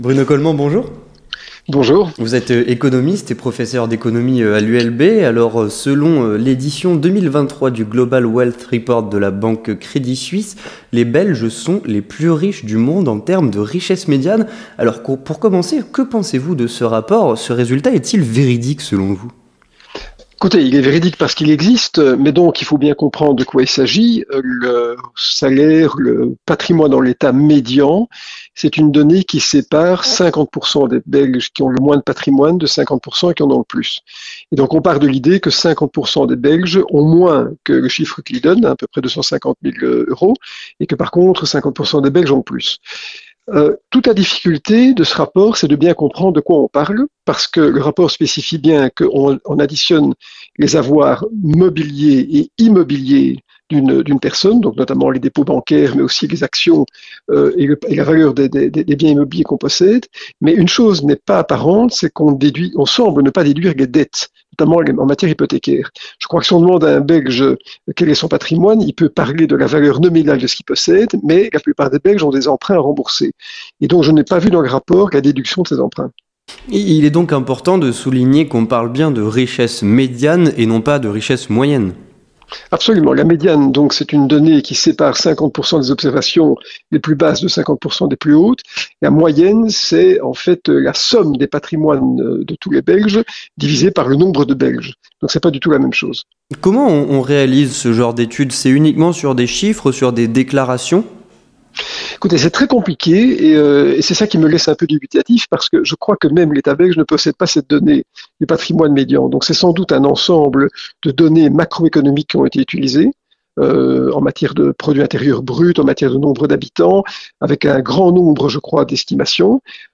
Avec Bruno Colmant, économiste et professeur d'économie à l'ULB